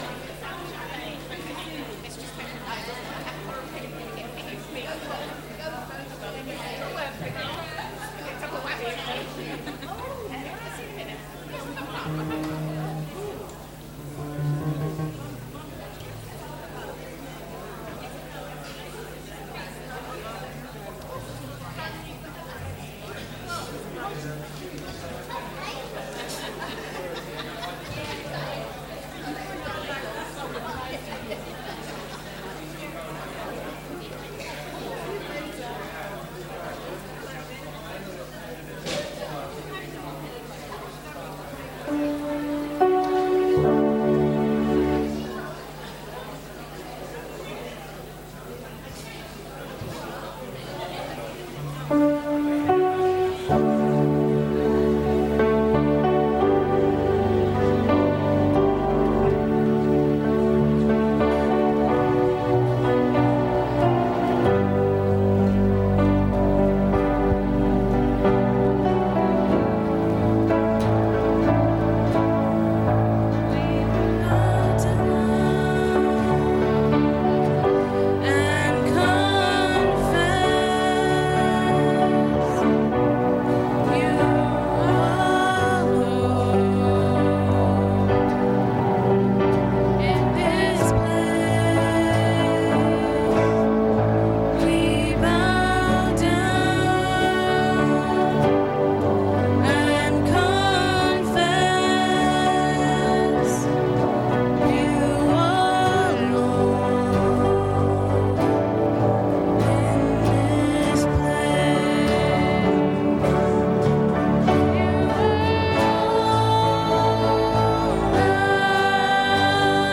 All-age service - Sittingbourne Baptist Church
Service Audio